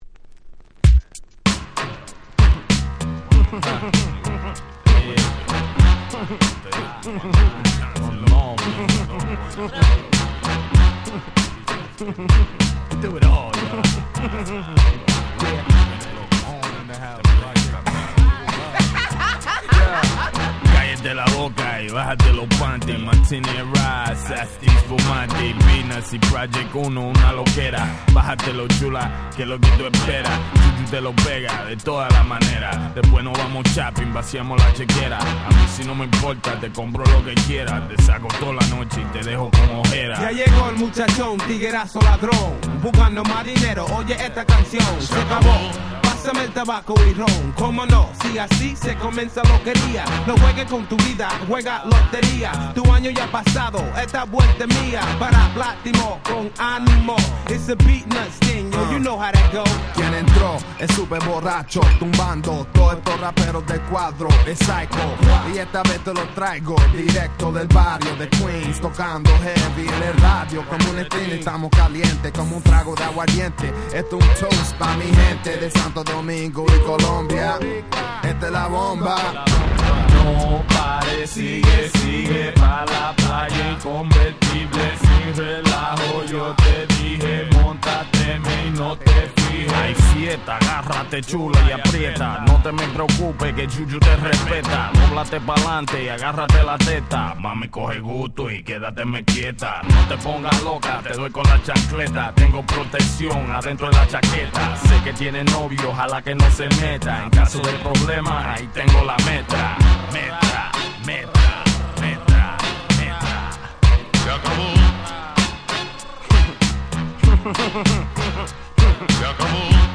99' Smash Hit Hip Hop !!
90's Boom Bap ブーンバップ